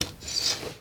Door_open.R.wav